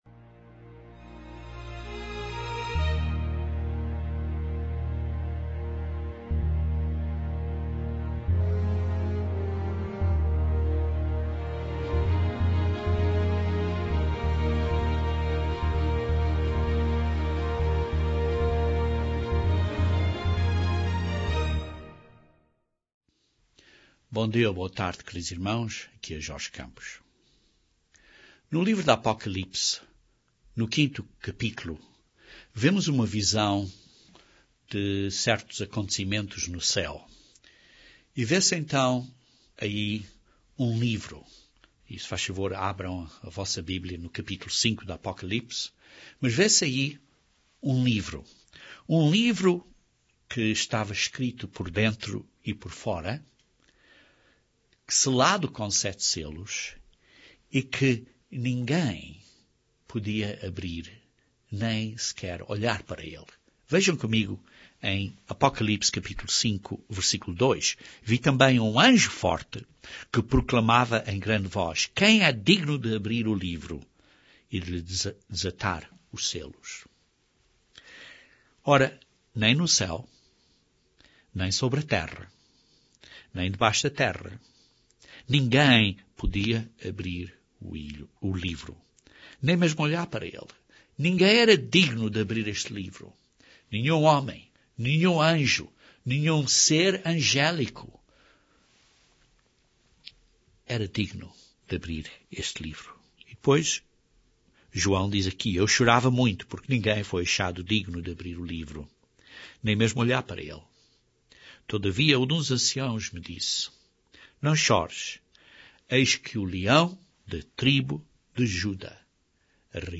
Jesus Cristo como o segundo Adão veio destruir o reino de Satanás, o pecado e a morte. Este sermão analisa esta missão do segundo Adão.